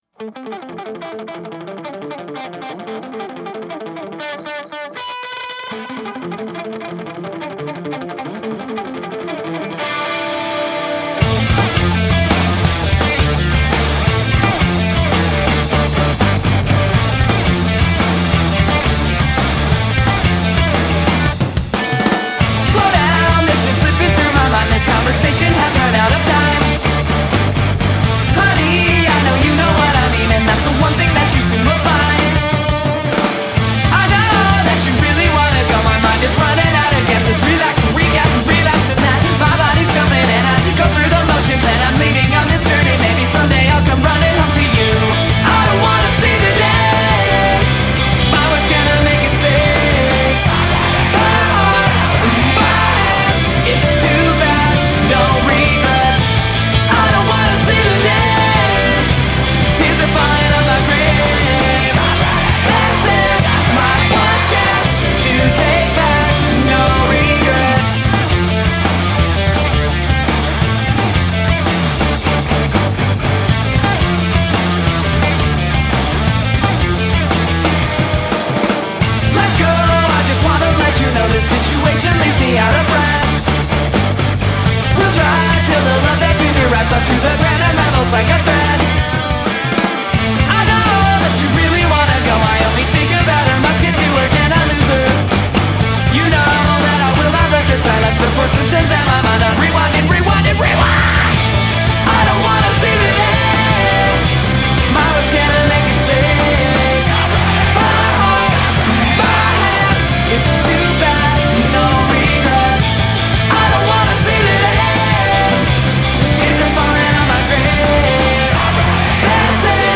is in 4/4 and still rocks